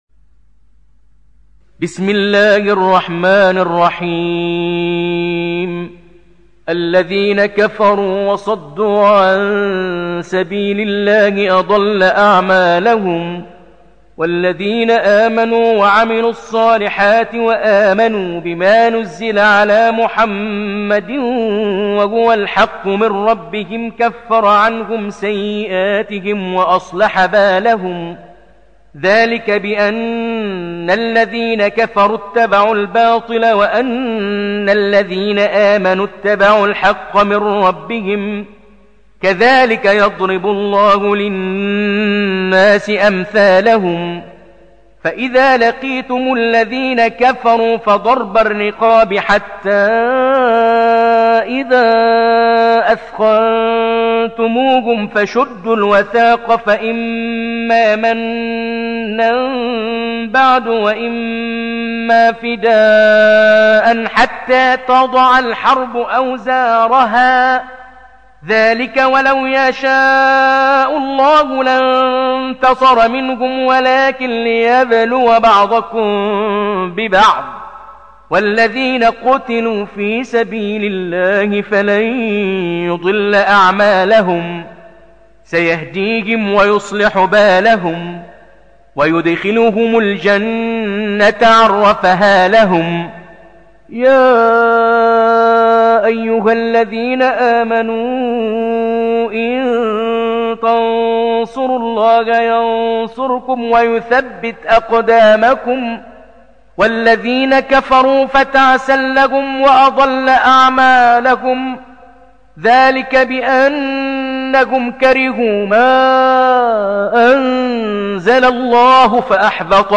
برواية حفص عن عاصم